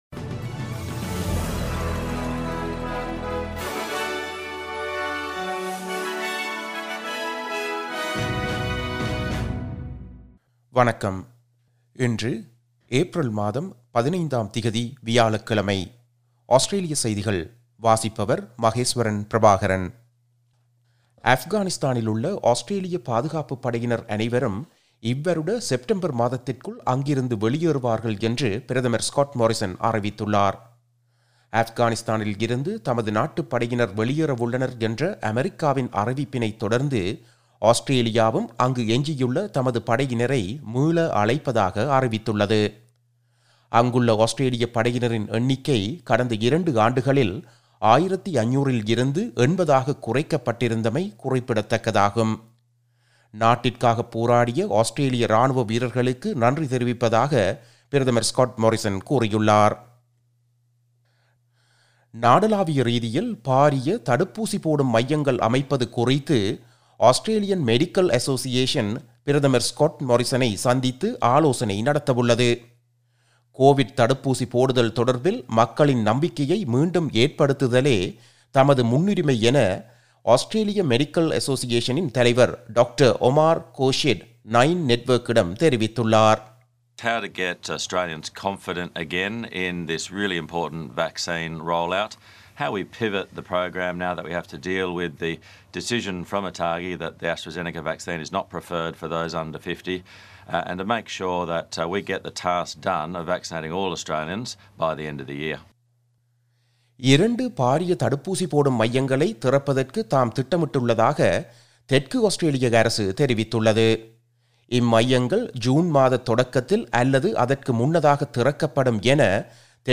Australian news bulletin for Thursday 15 April 2021.